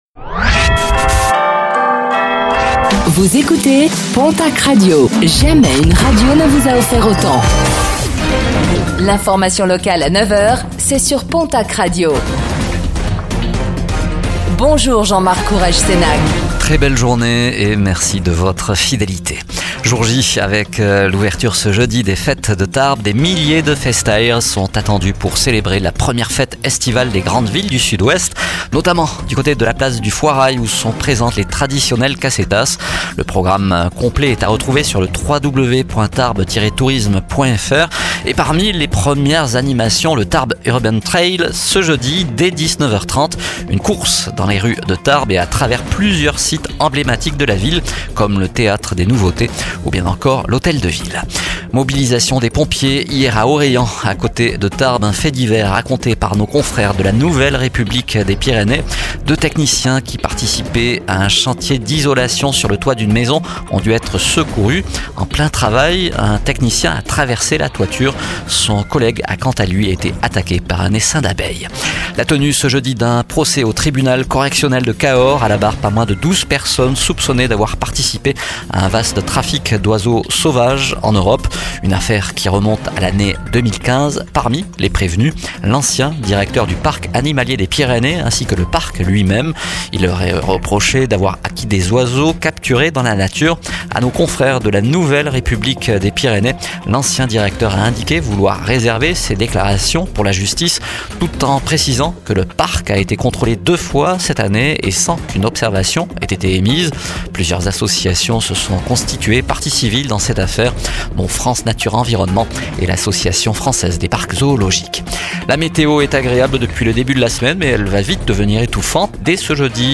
Réécoutez le flash d'information locale de ce jeudi 19 juin 2025